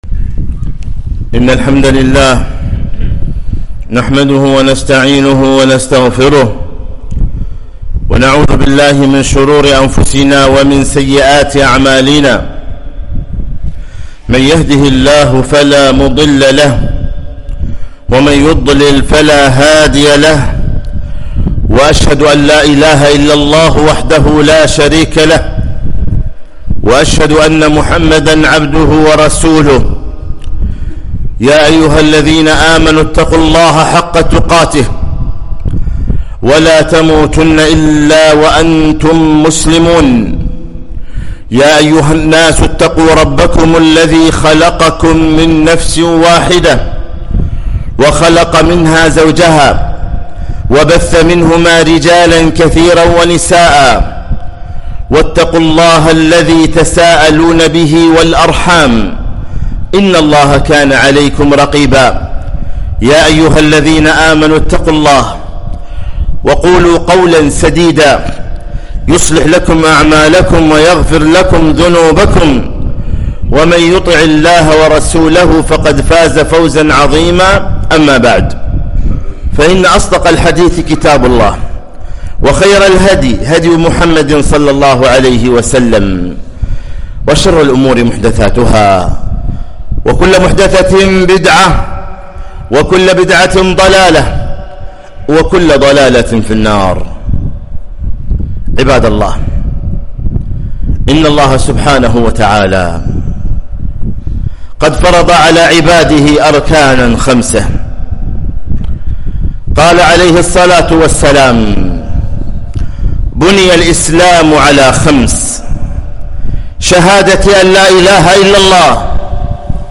خطبة - ( التشويق إلى حج بيت الله العتيق )